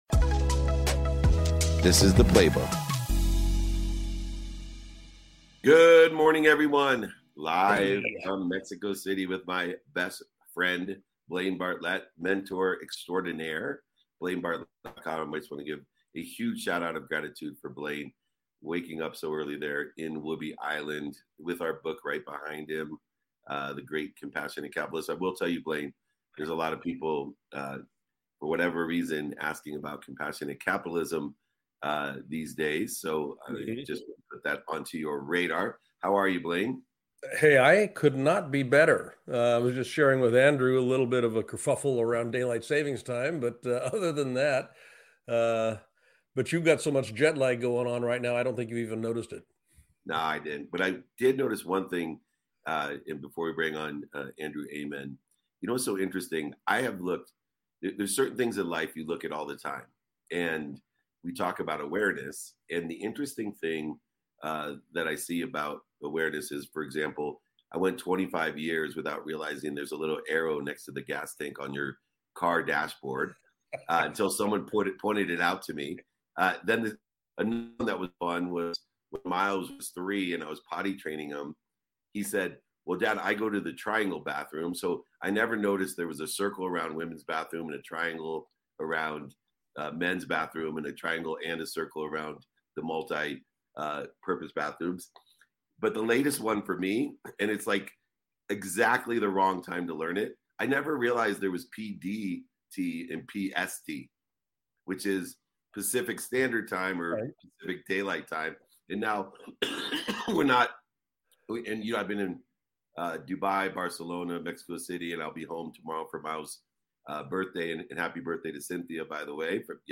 had a fireside chat with fellow business leaders about leadership skills for modern entrepreneurs.